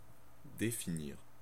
Ääntäminen
France (Normandie): IPA: /de.fi.niʁ/